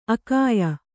♪ akāya